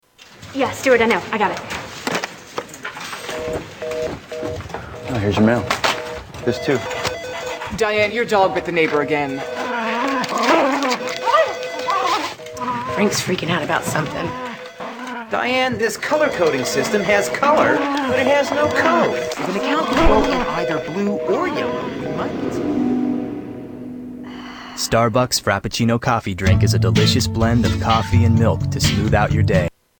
Starbucks ad at office